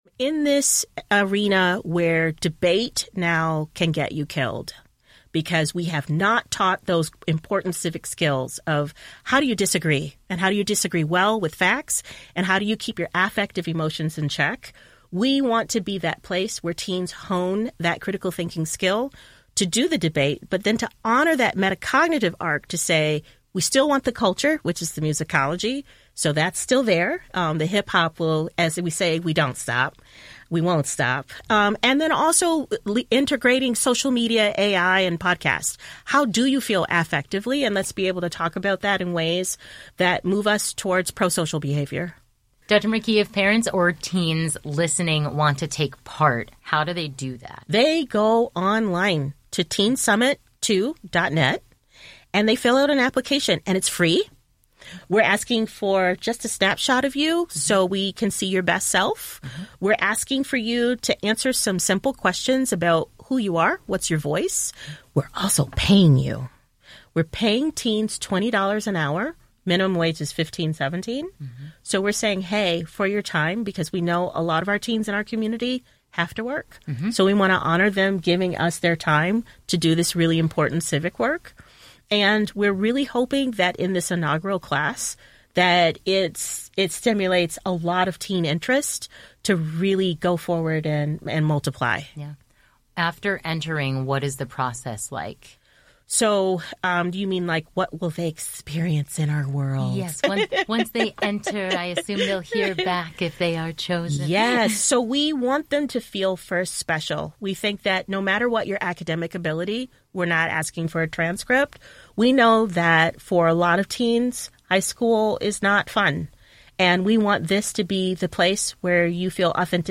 Both serve as executive producers for the program.